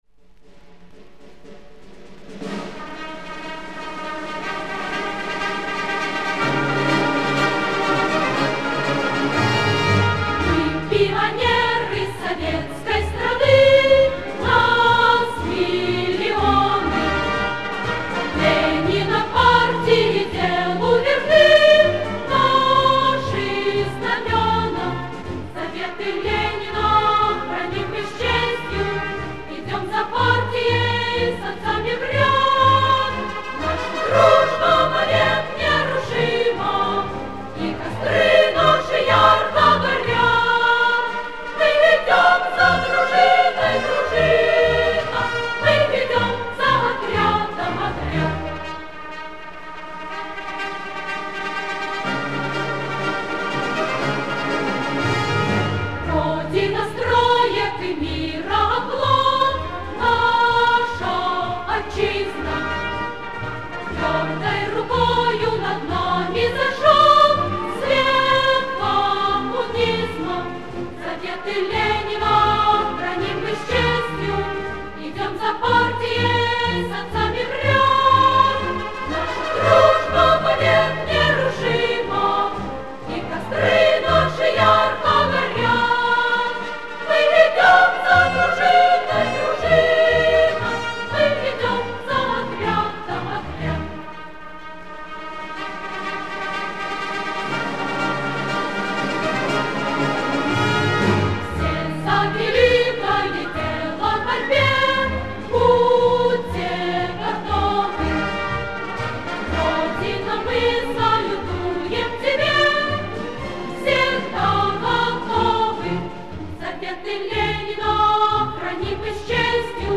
Марши